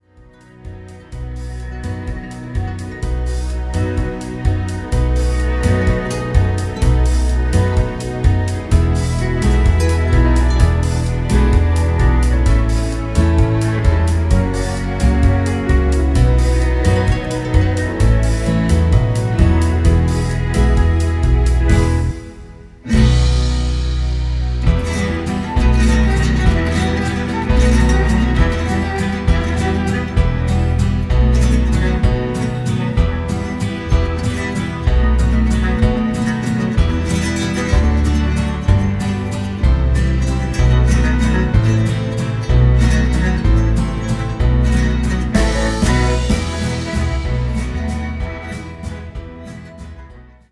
(sans choeurs)